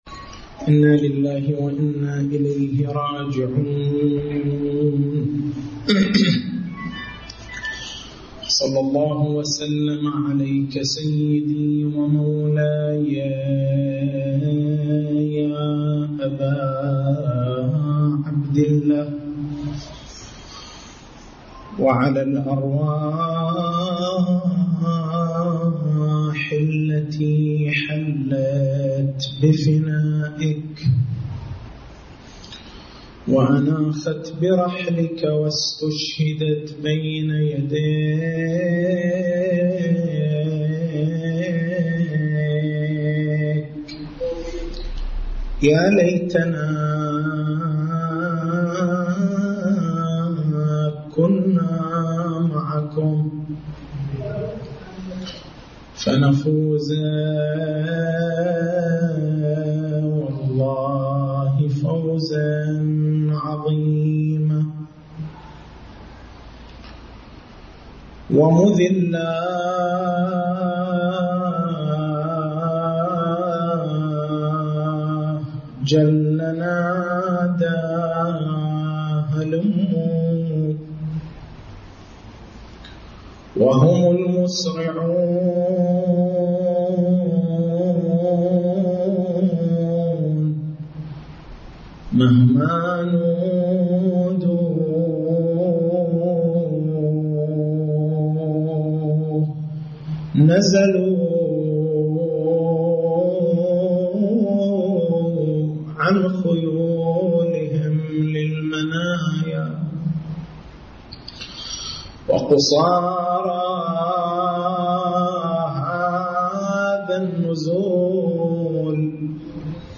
نص المحاضرة